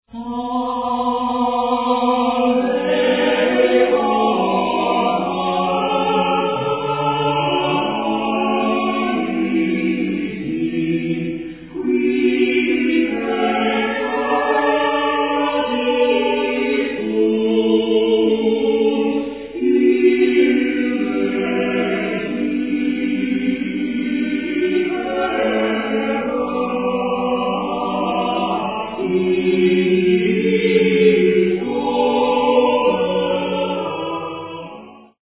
tenor
Baritone
organist